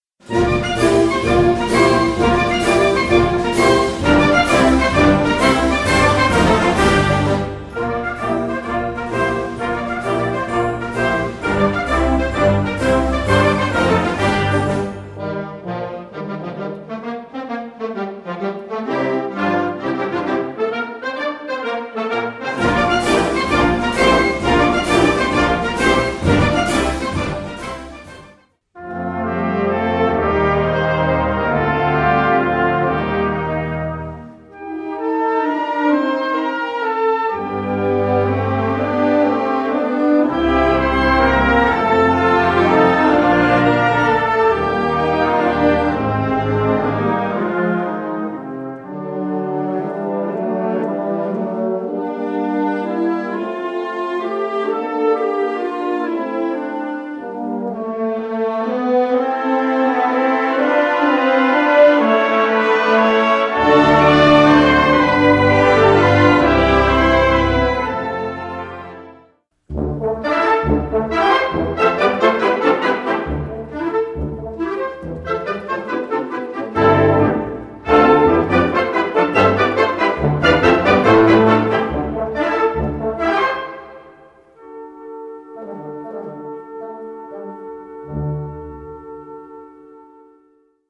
Subcategorie Hedendaagse blaasmuziek (1945-heden)
Bezetting Ha (harmonieorkest)